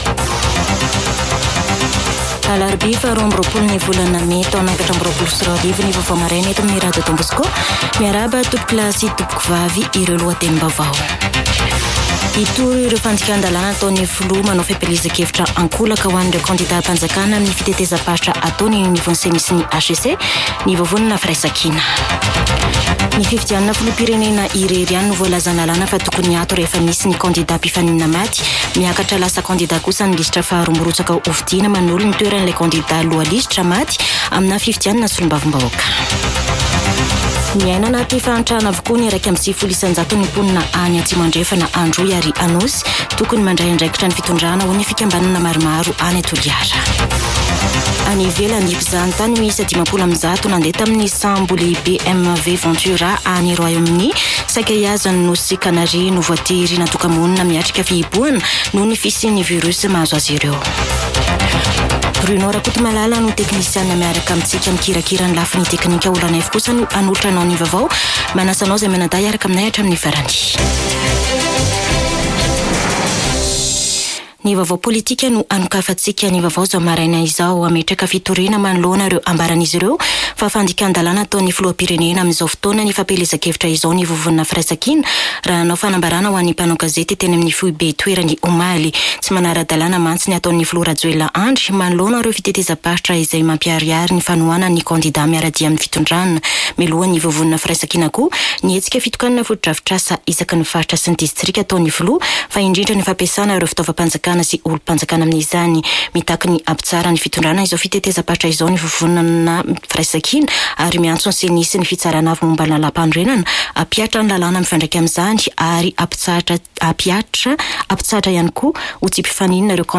[Vaovao maraina] Alarobia 22 mey 2024